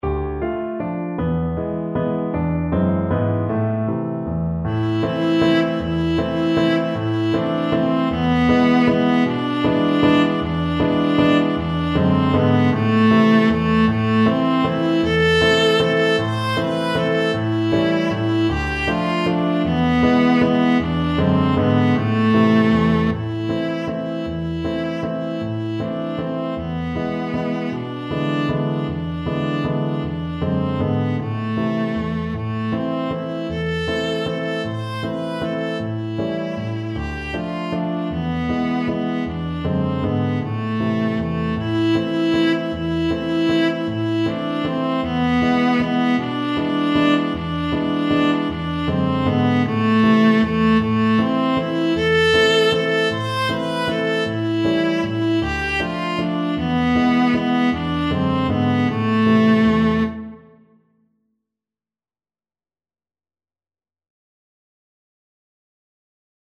Traditional Trad. Tumbalalaika Viola version
Viola
A minor (Sounding Pitch) (View more A minor Music for Viola )
One in a bar . = c.52
3/4 (View more 3/4 Music)
A4-C6
Traditional (View more Traditional Viola Music)